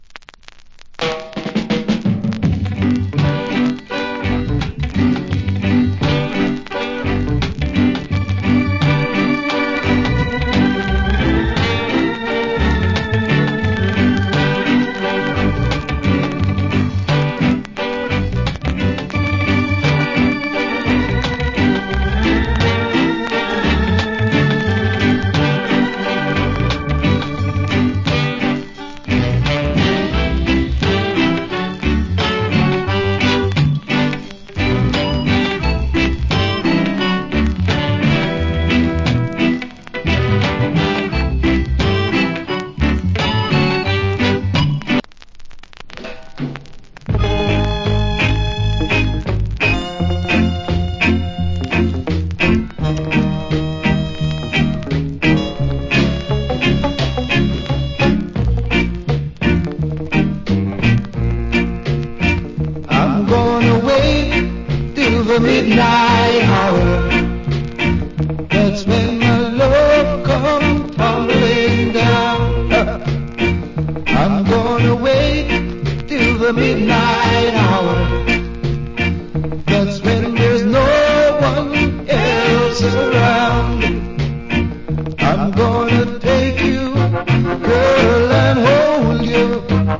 Cool Rock Steady Inst.